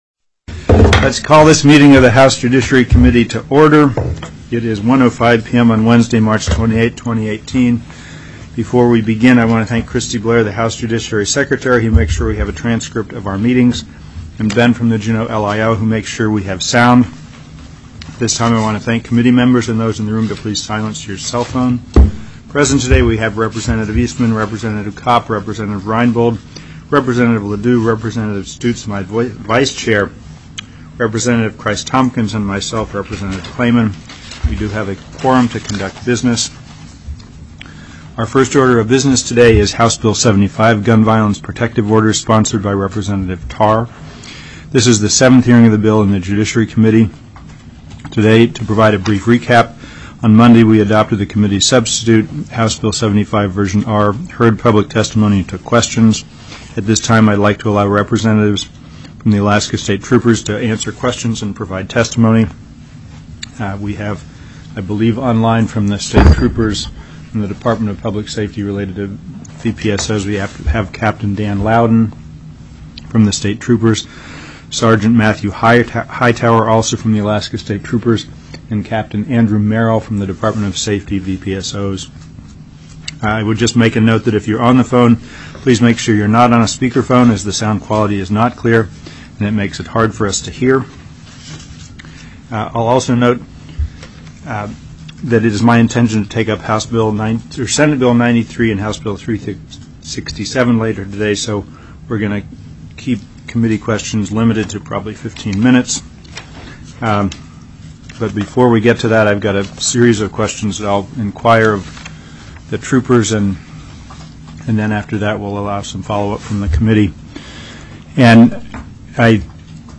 TELECONFERENCED
Public Testimony